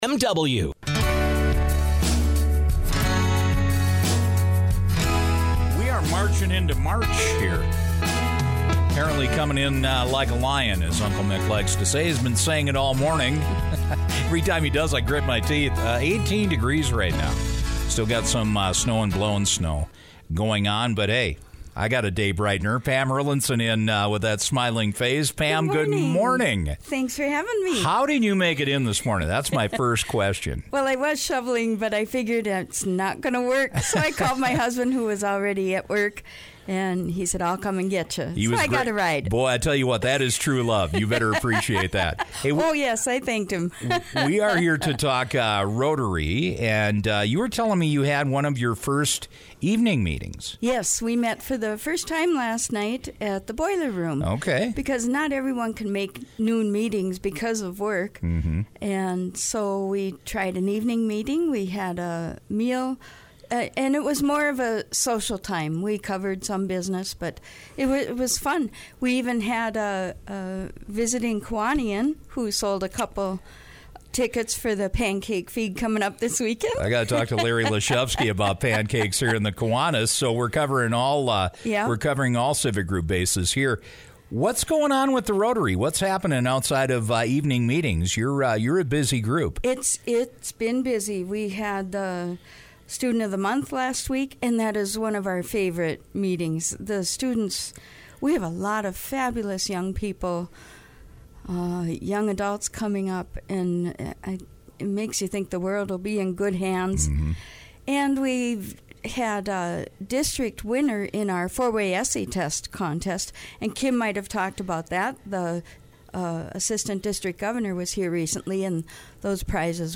The local Rotarians are back with their blood and health screening event in April. Local Rotarian